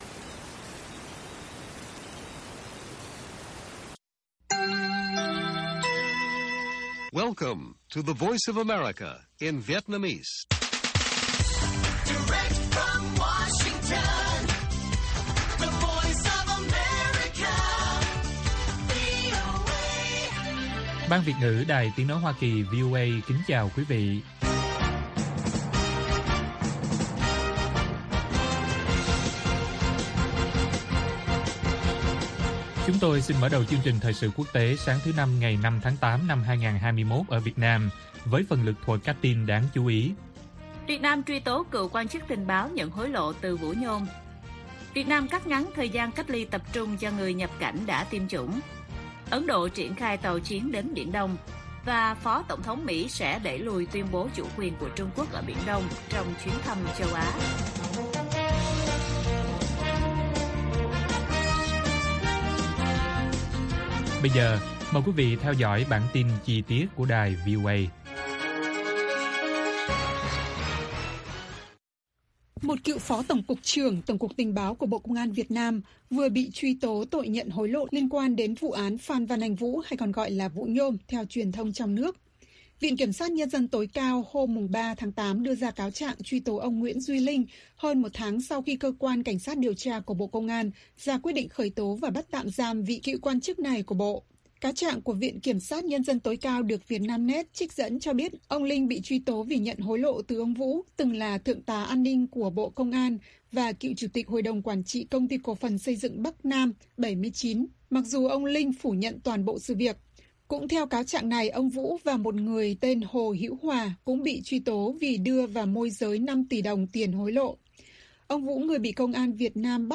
Bản tin VOA ngày 5/8/2021